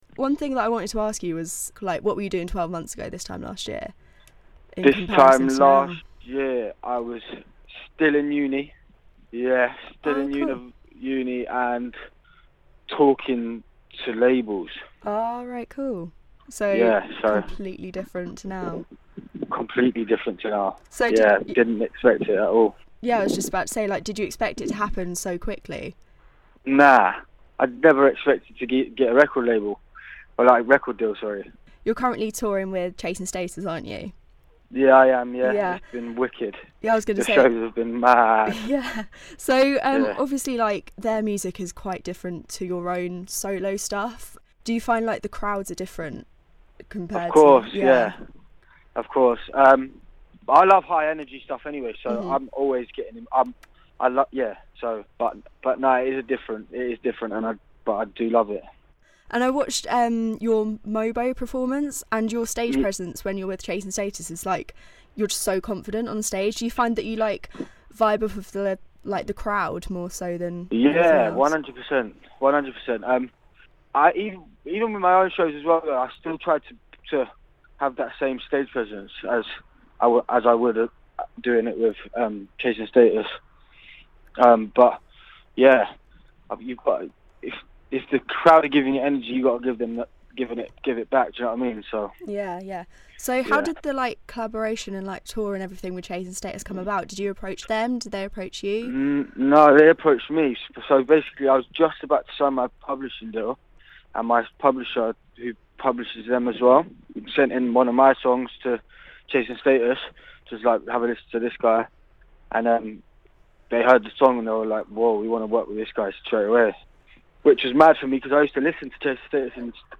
INTERVIEW: TOM GRENNAN chats about an insane 2016